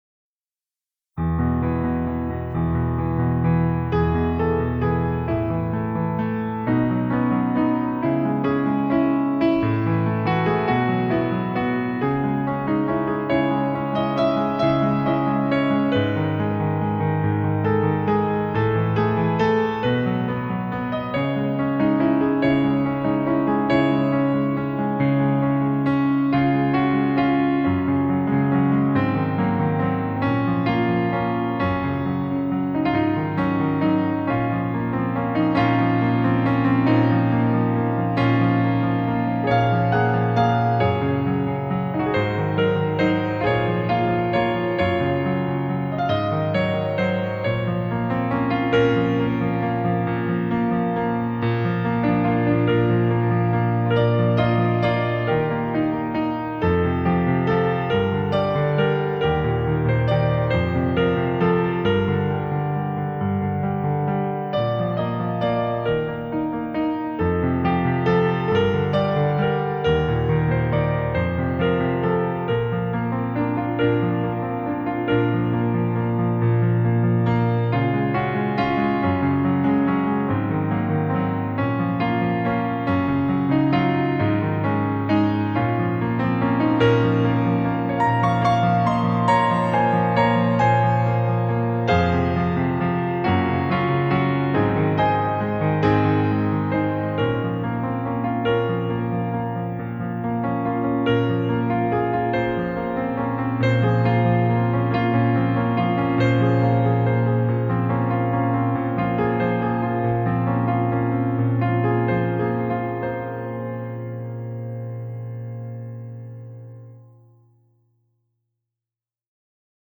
除了以往的柔美、詩意之外，琴聲中更多了一份成熟與洗鍊。
簡潔又和緩的曲調，能讓人沈浸在輕快感之中，優雅的旋律繚繞不絕於耳。